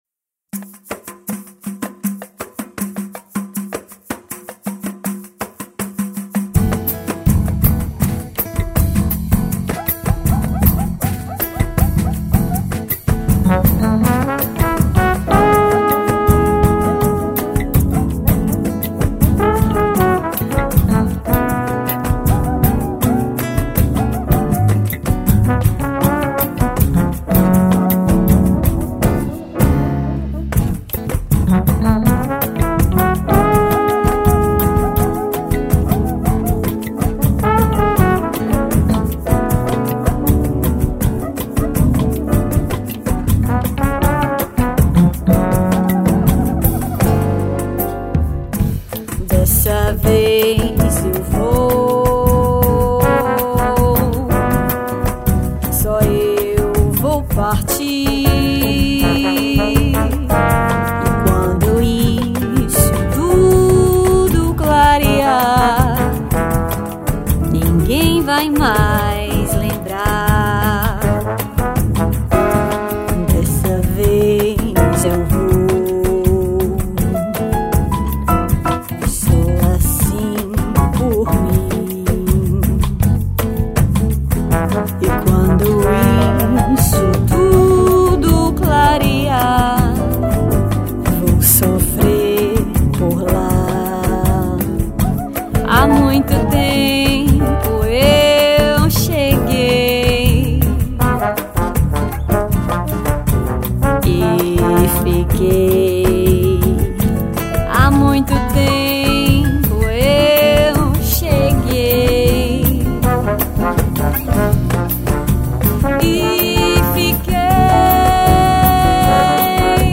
2595   04:47:00   Faixa: 7    Mpb